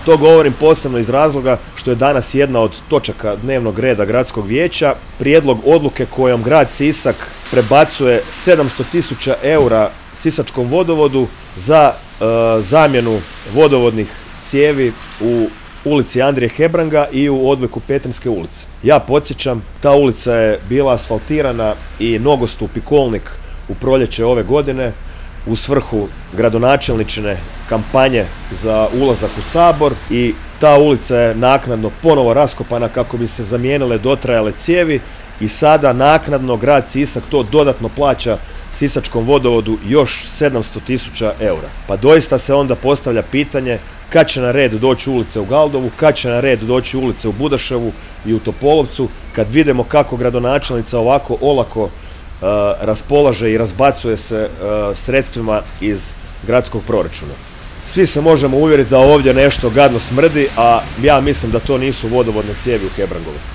Gradska organizacija HDZ-a Sisak održala je u petak, 22. studenog 2024. godine, tiskovnu konferenciju ispred odgojno-obrazovnog kompleksa u Galdovačkoj ulici, na temu: „Poziv na otvorenje vrtića u Galdovu”.